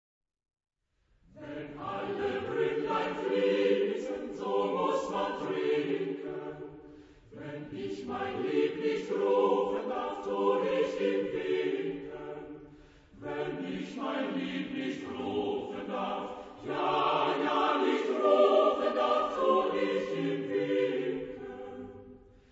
Harm. : Silcher, Friedrich (1789-1860) [ Allemagne ]
Genre-Style-Forme : Lied ; Profane
Caractère de la pièce : vivant ; léger
Type de choeur : SATB  (4 voix mixtes )
Tonalité : sol majeur